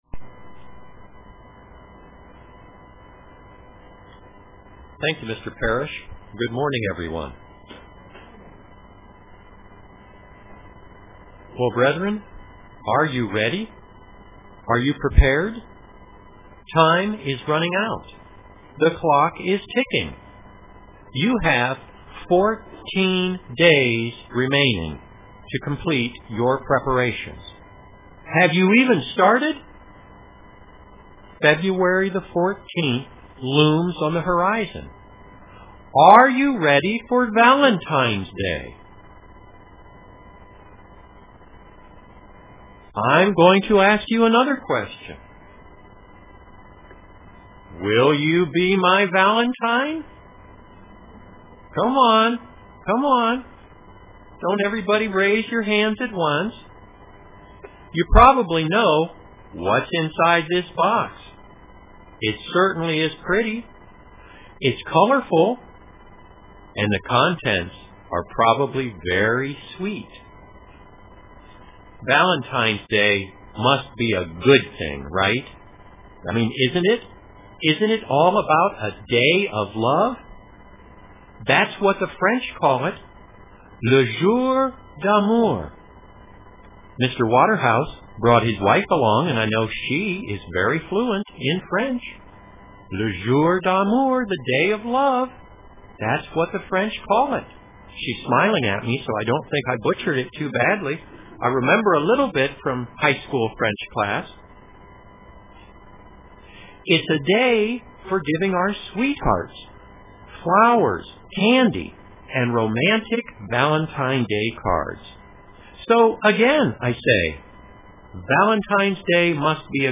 Print What's Wrong with Valentine's Day UCG Sermon Studying the bible?